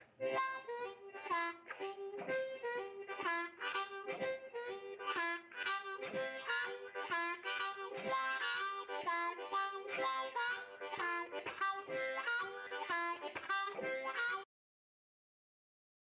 I think right now the biggest difference is note clarity (his aren’t muffled whereas mine often are) and also he is able to produce that “springiness” in them each time when needed.